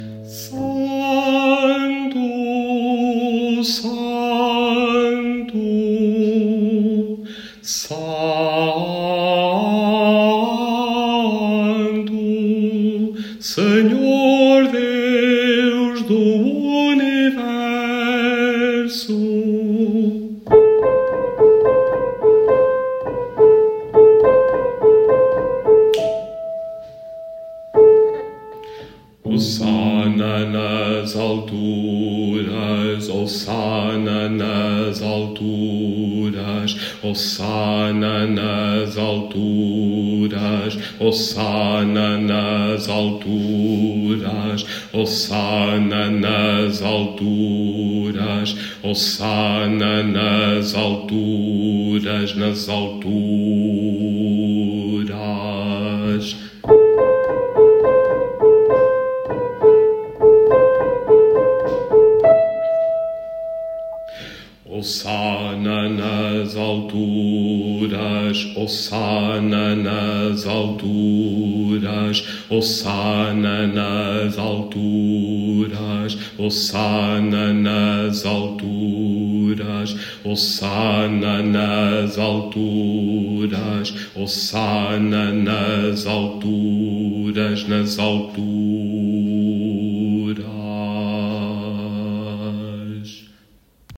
Baixo2